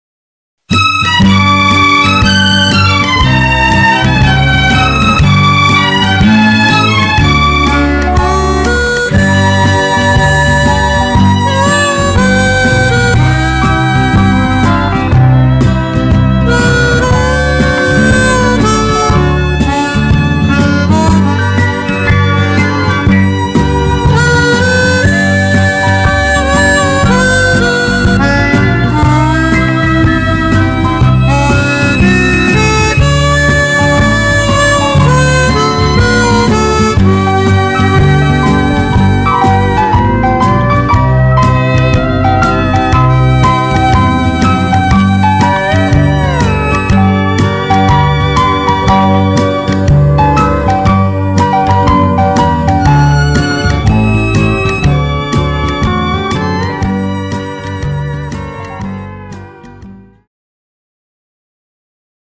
Cued Sample
Two Step